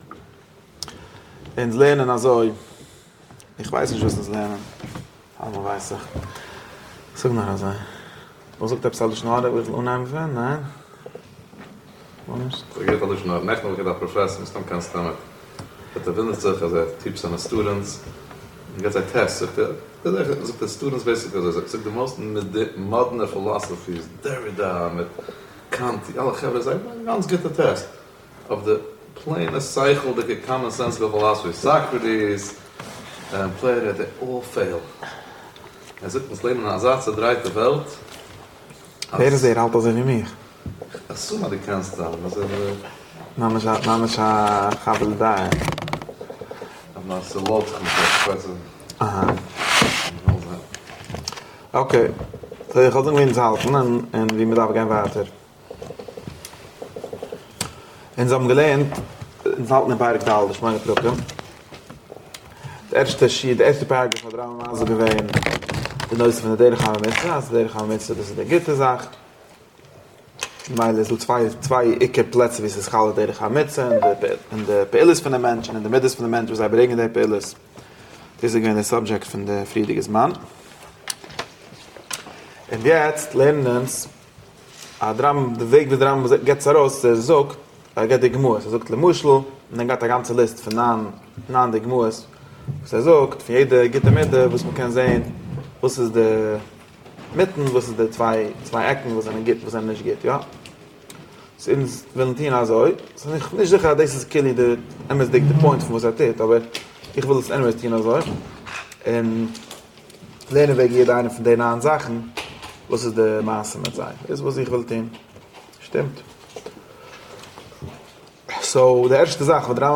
דער שיעור אונטערזוכט פארוואס דער רמב"ם'ס ליסט פון מידות איז נישט דעפיניטיוו, און פארגלייכט צוויי פונדאמענטאלע וועגן צו קאטעגאריזירן כאראקטער טרייטס: איינער באזירט אויף די סטרוקטור פון די נשמה (ווי ספירות און חלקי הנפש), און דער אנדערער באזירט אויף פראקטישע נושאים און אביעקטן (ווי געלט, עסן, כבוד).